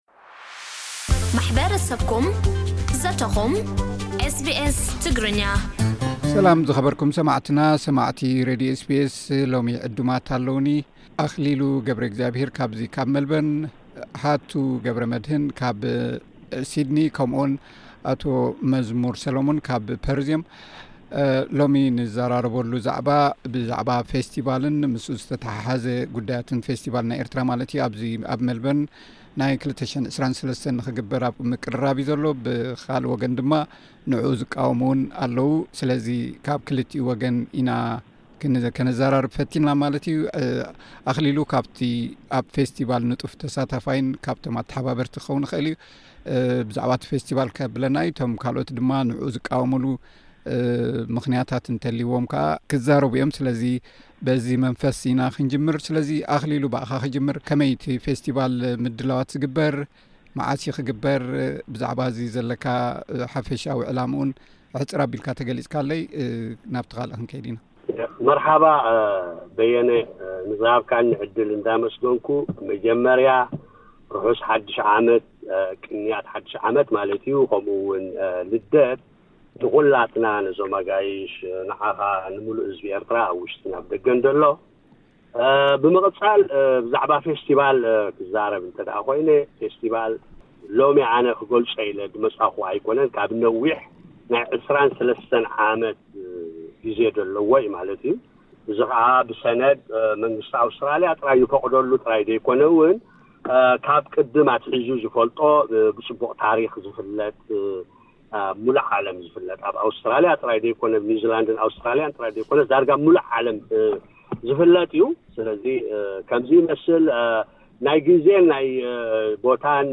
ክፉት ዘተ